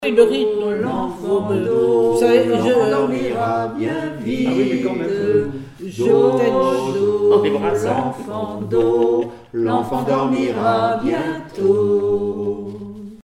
berceuse
Comptines et formulettes enfantines
Pièce musicale inédite